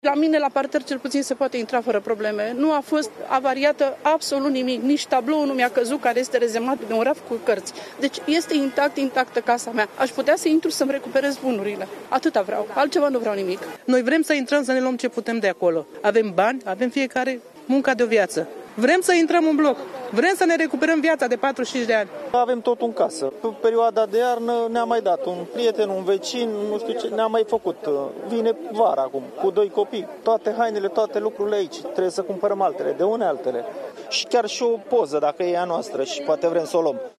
„La mine, la parter, cel puțin se poate intra fără probleme. Nu a fost avariat absolut nimic. Nici tablouul nu mi-a căzut, care este rezemat de un raf cu cărți. Deci, este intactă casa mea.  Aș putea să intru să-mi recuperez bunurile. Atâta vreau. Altceva nu vreau nimic”, a declarat o femeie.
„Noi vrem să intrăm să ne luăm ce putem de acolo. Avem bani, avem fiecare munca de o viață. Vrem să intrăm în bloc. Vrem să ne recuperăm viața de 45 de ani”, a spus o altă femeie.
17apr-20-Vox-–-Locatari-–-Vrem-sa-ne-recuperam-munca-de-o-viata.mp3